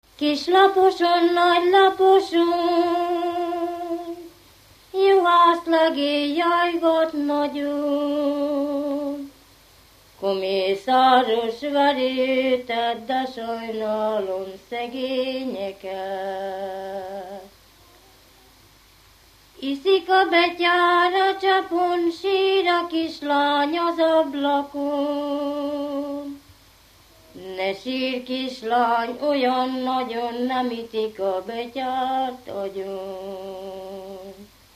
Alföld - Bács-Bodrog vm. - Dávod
ének
Stílus: 1.2. Ereszkedő pásztordalok
Szótagszám: 8.8.8.8
Kadencia: 7 (5) b3 1